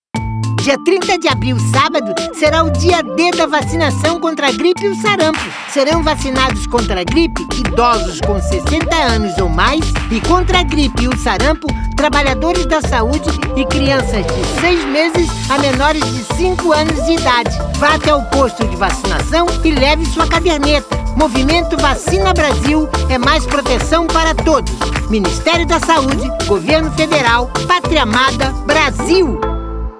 Spot - Dia D - Vacinação Gripe e Sarampo 30seg